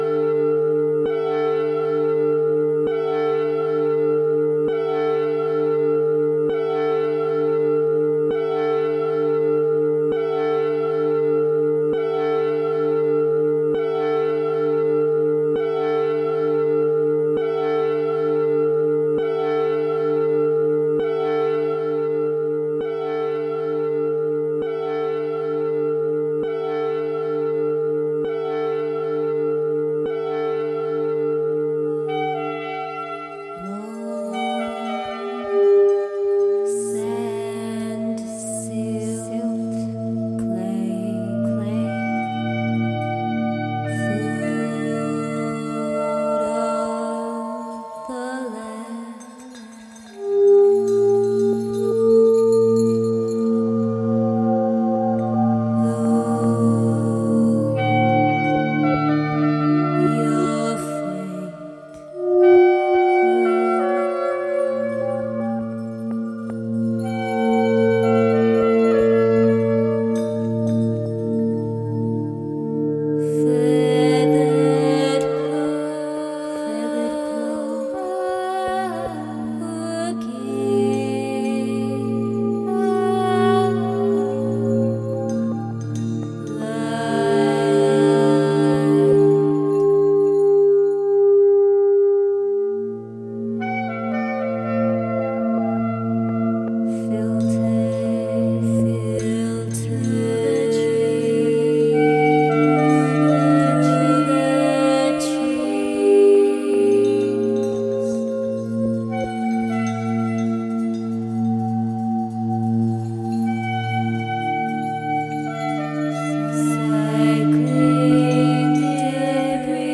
Underground, local, global, universal music and people.